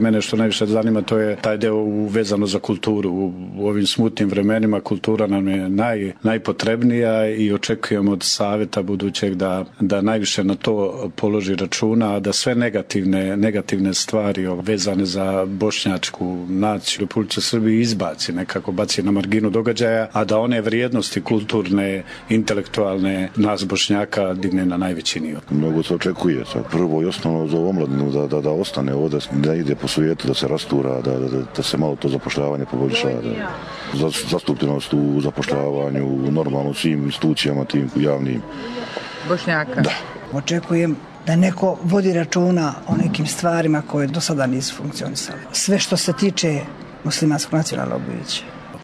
Građani na glasačkom mestu u Priboju